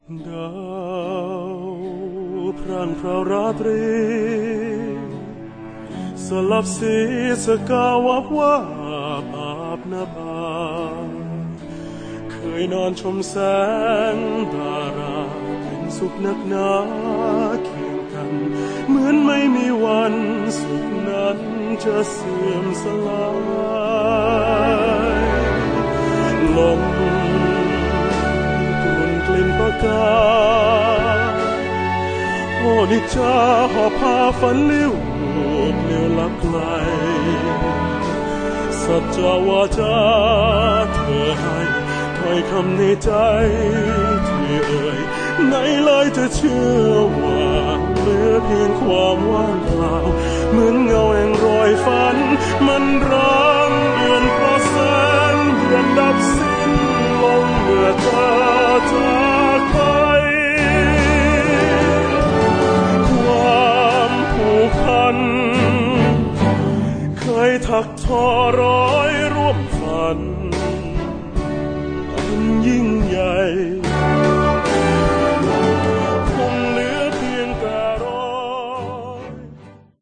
ประเภทเพลงไทยสากล
เจ้าของเสียงเทเนอร์ระดับแถวหน้า